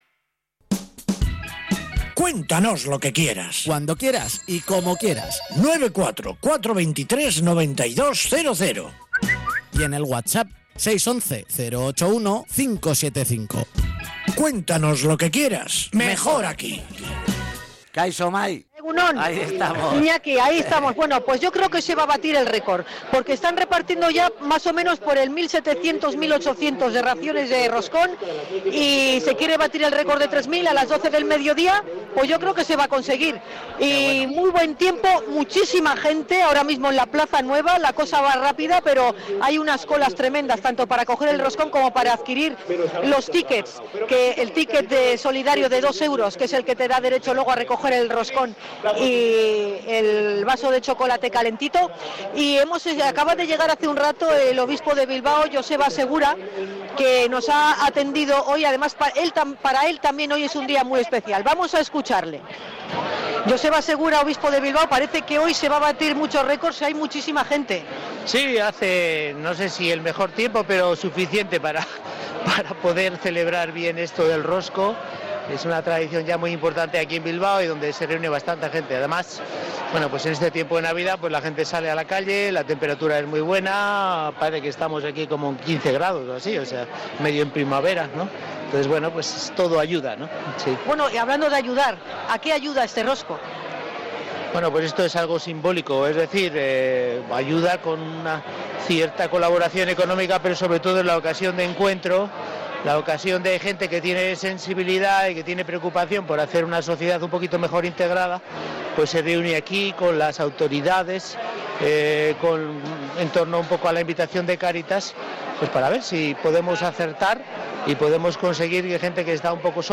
Hablamos con Joseba Segura, obispo de Bilbao, que cumple 40 años de ordenación sacerdotal
Hemos recogido las opiniones de voluntarias de Cáritas y del público presente.